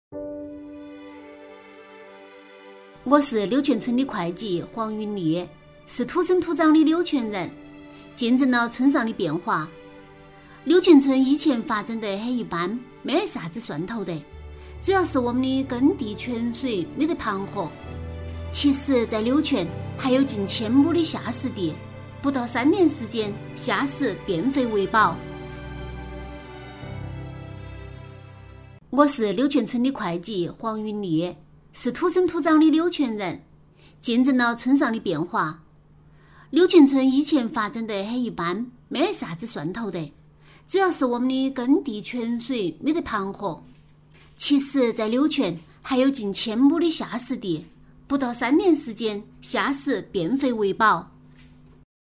女28-四川话-素人感 自述
女28-四川话-素人感 自述.mp3